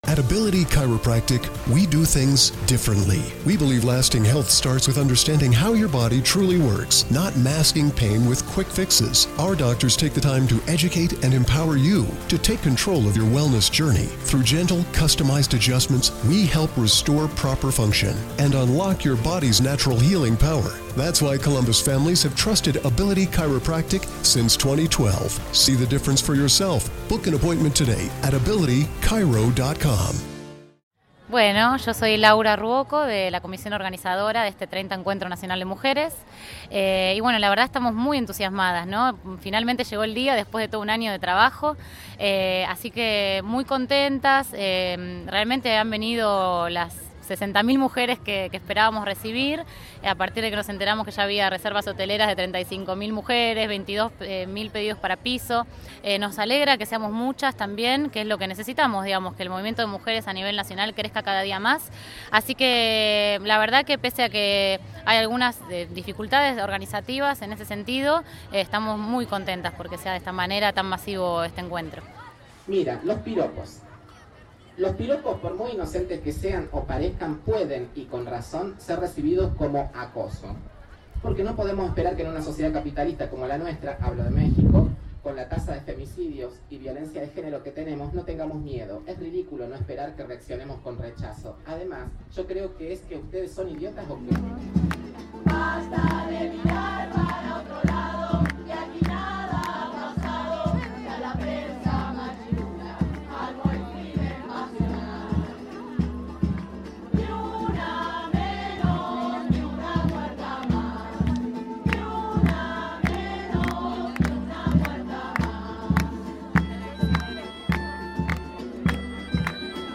Mientras nos preparamos para Rosario, compartimos algunos momentos de lo que sucedió durante el 30 Encuentro Nacional de Mujeres en Mar del Plata.